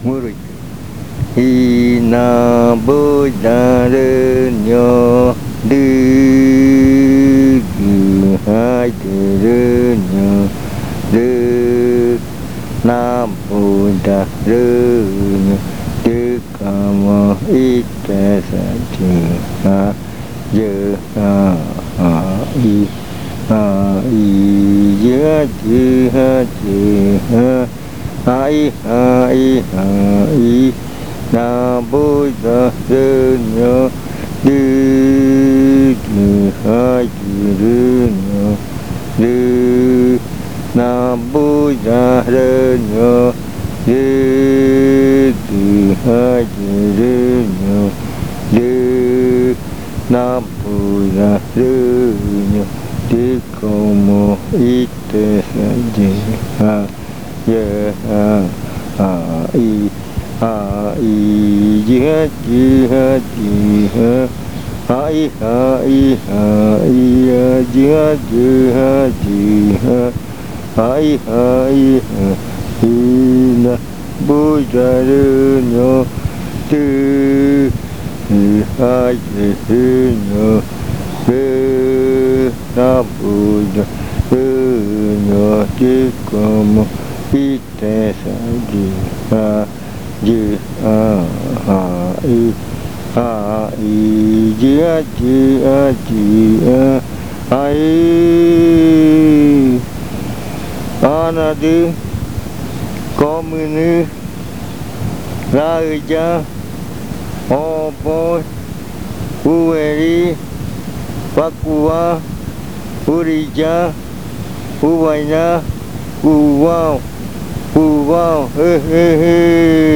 Leticia, Amazonas
Canto que tiene adivinanza, habla de la mujer del atardecer.
Chant that has a riddle, it tells of the the woman of the sunset.